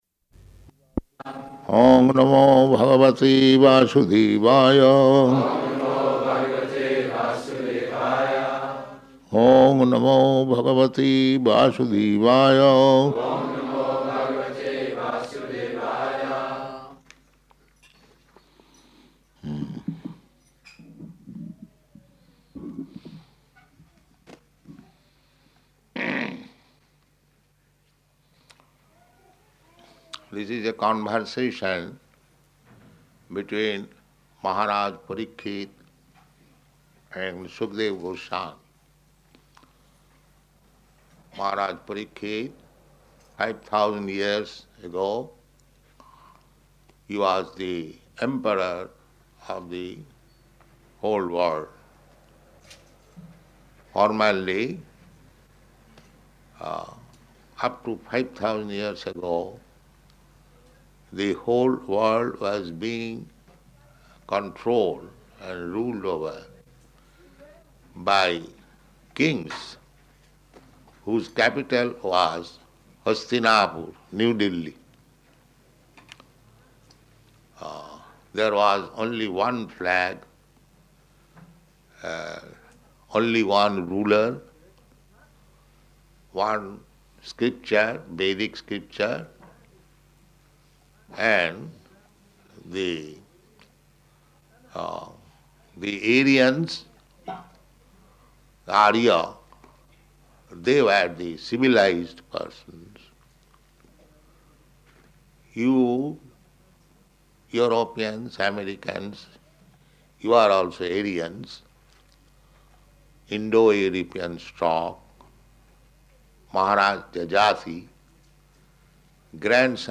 Śrīmad-Bhāgavatam Lecture